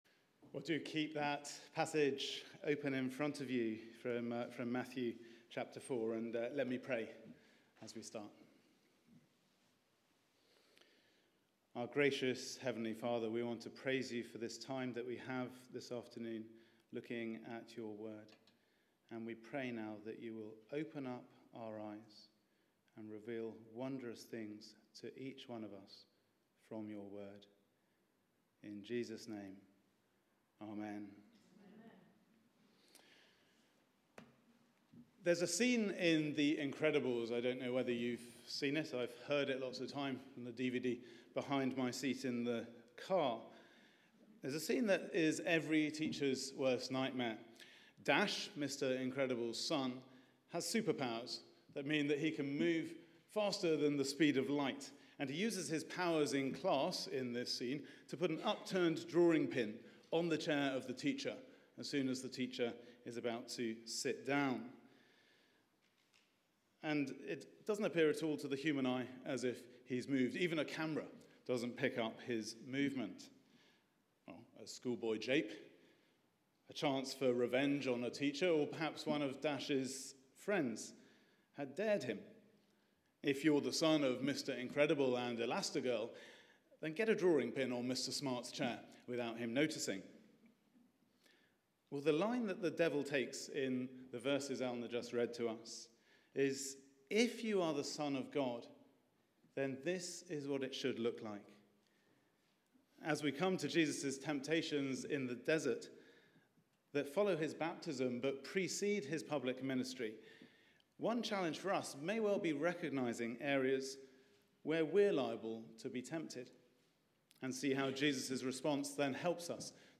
Media for 4pm Service on Sun 22nd Jan 2017 16:00 Speaker
Series: Following Jesus Theme: Temptation of Jesus Sermon Search the media library There are recordings here going back several years.